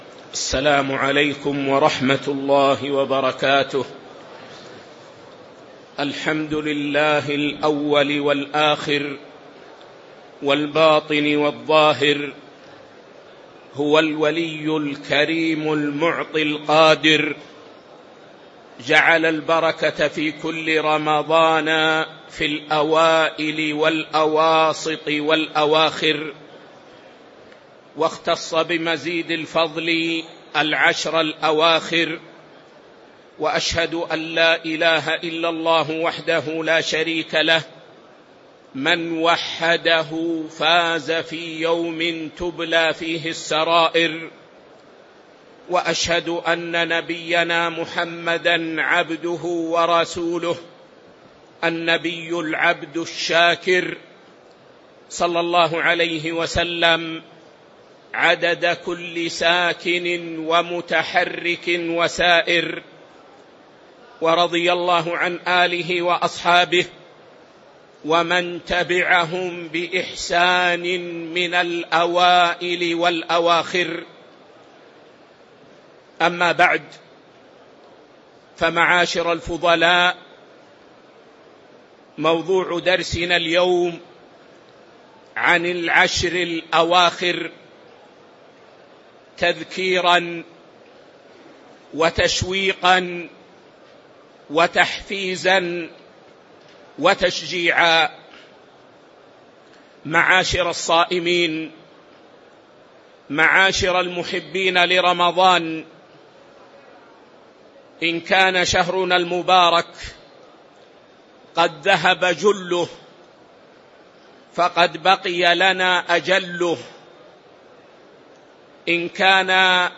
تاريخ النشر ١٩ رمضان ١٤٤٦ هـ المكان: المسجد النبوي الشيخ